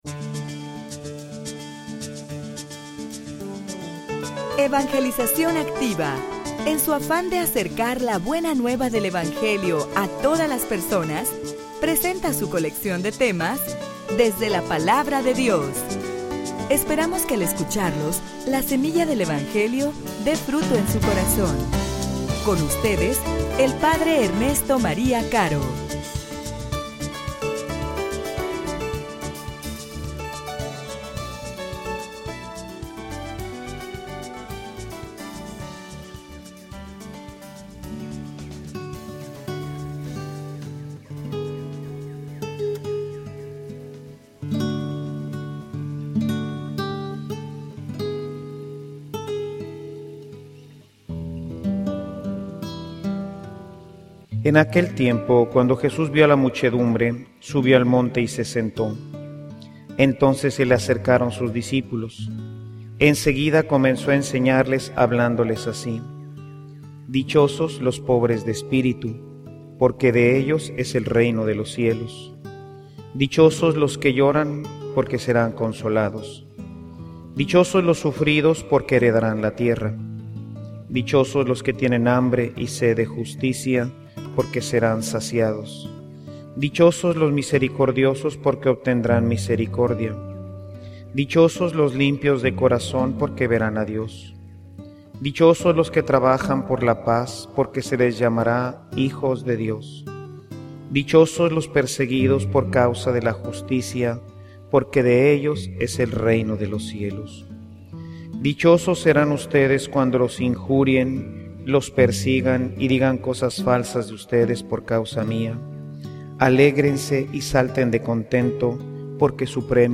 homilia_Sean_santos_imperativo_cristiano.mp3